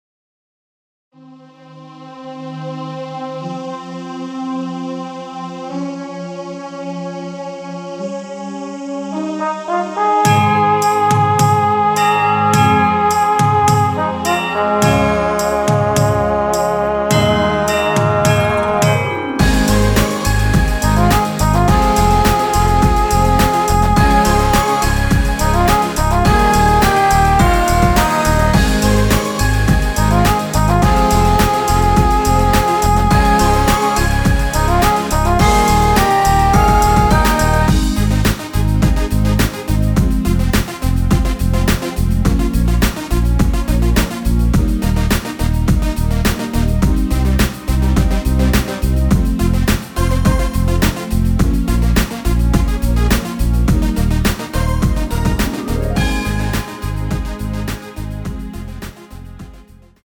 원키에서(-2)내린 MR입니다.
F#
앞부분30초, 뒷부분30초씩 편집해서 올려 드리고 있습니다.